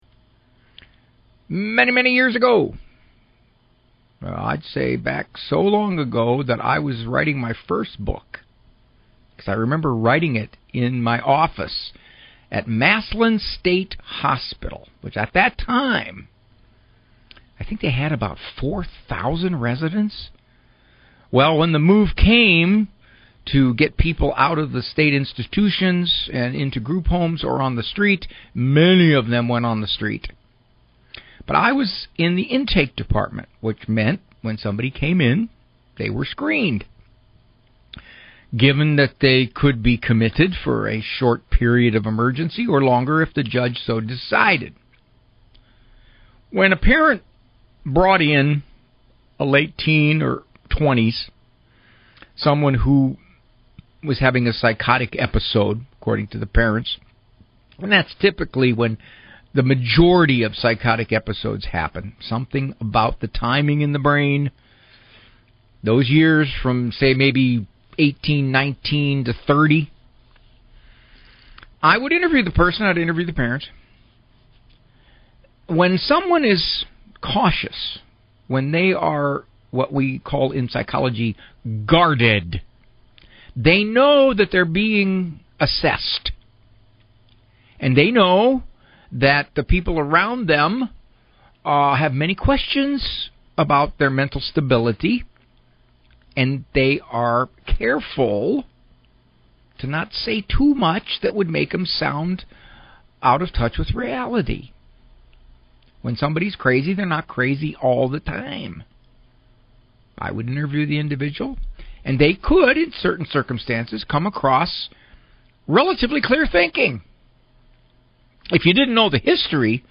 The interview went on one minute too long...
Intvw-1-min-too-long.mp3